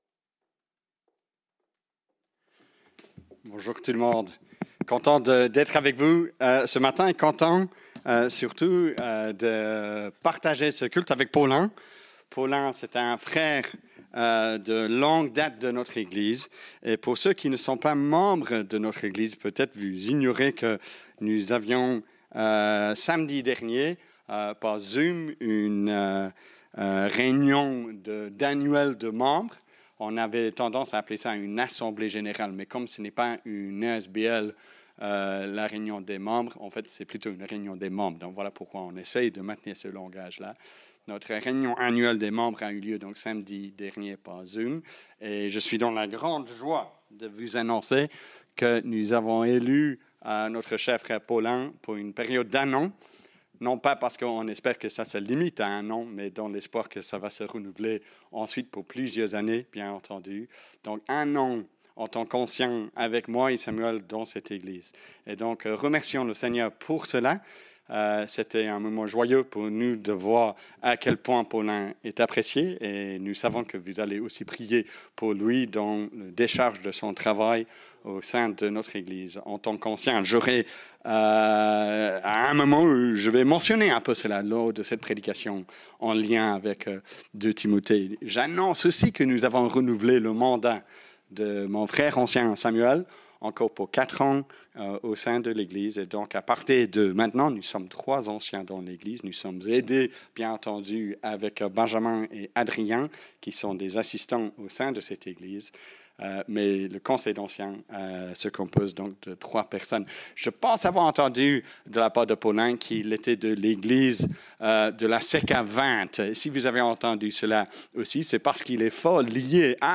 culte-du-2-mai-2021-epe-bruxelles-woluwe.mp3